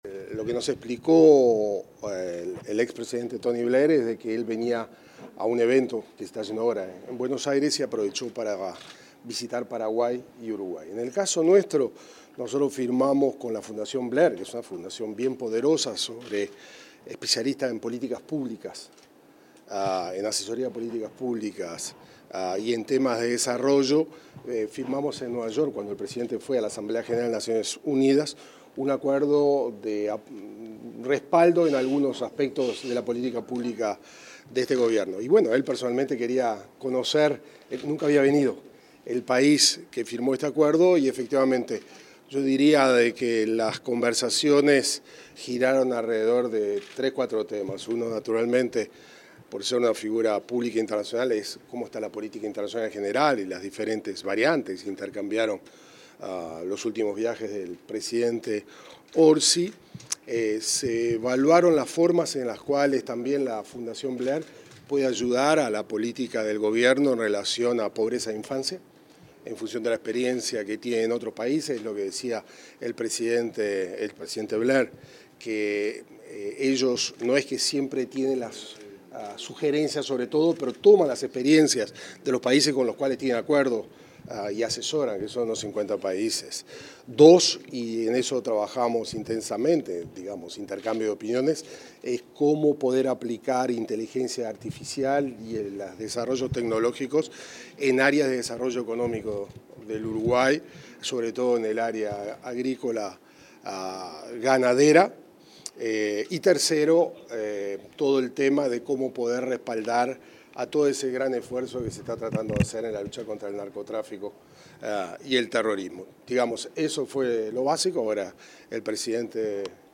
Declaraciones del ministro de Relaciones Exteriores, Mario Lubetkin